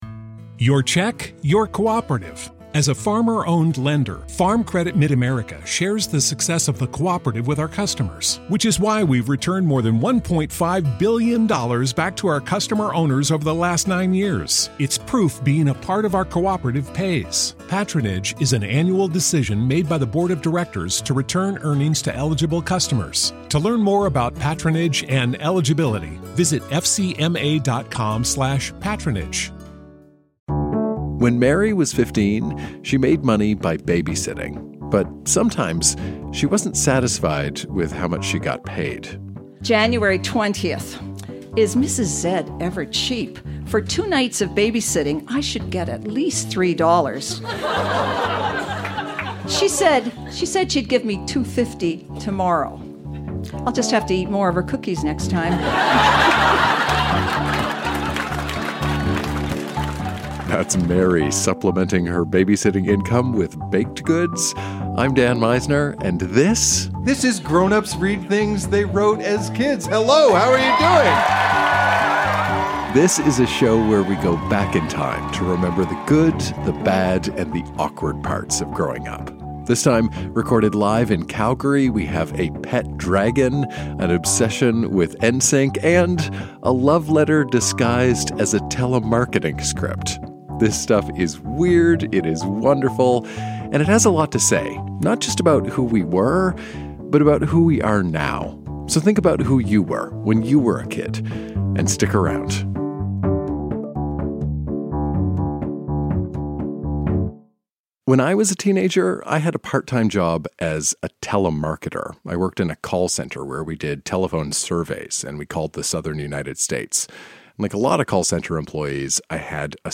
Recorded live in Calgary, AB.